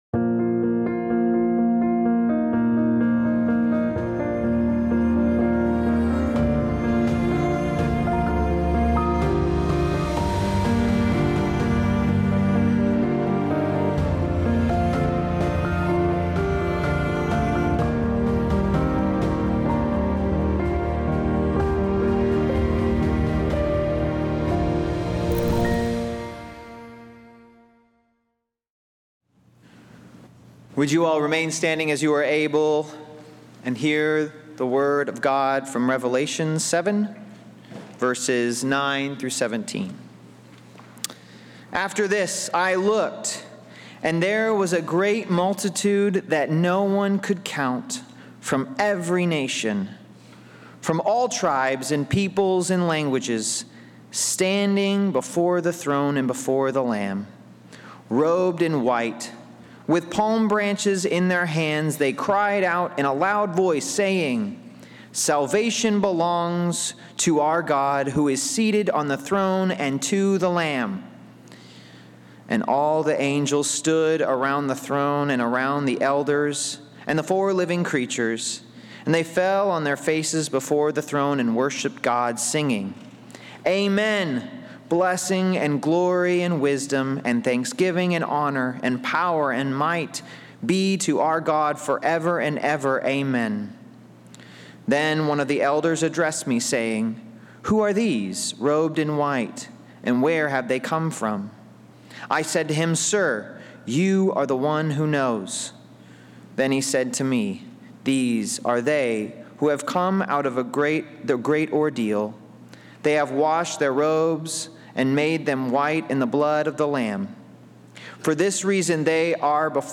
Service Type: Traditional
A Special Mother’s Day & Graduation Sunday Message This week, we celebrated both Mother’s Day and our graduating seniors with a powerful reminder from Revelation 7: the Lamb who was slain is now our Shepherd, walking with us through every moment of life.